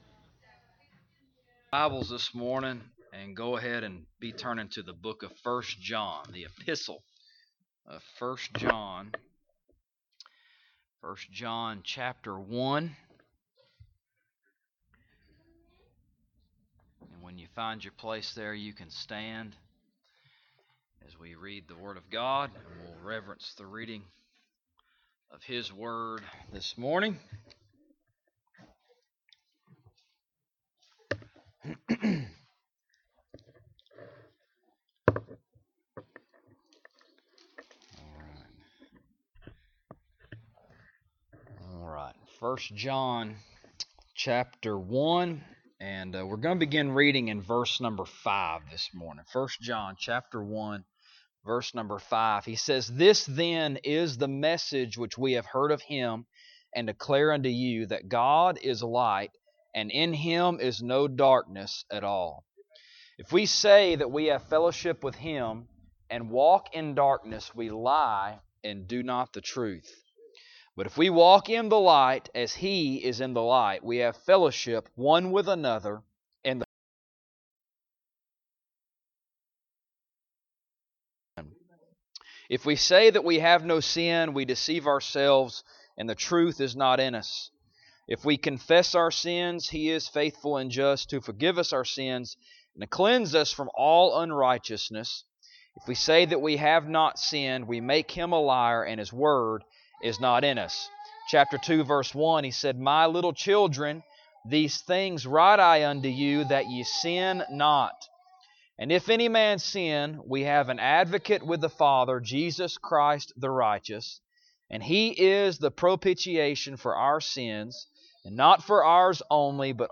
1 John Passage: 1 John 1:5 - 2:2 Service Type: Sunday Morning « What is Within Knowing to Know